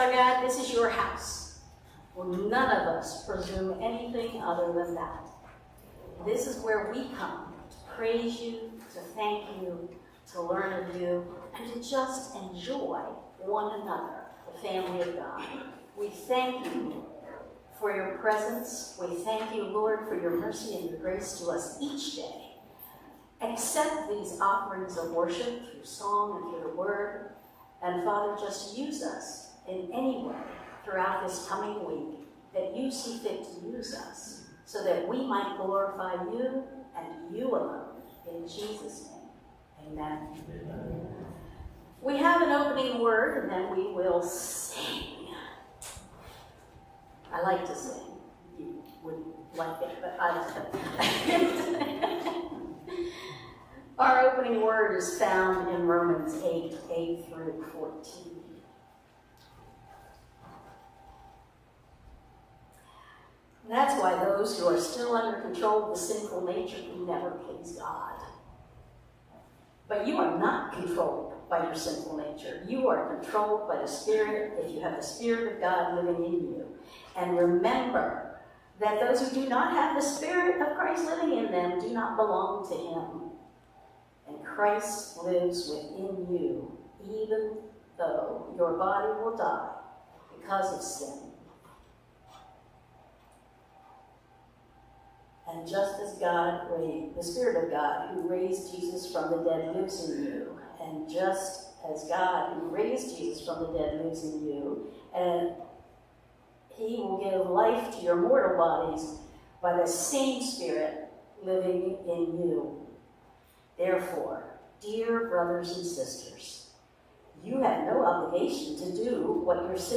Sunday Morning Service – July 14, 2024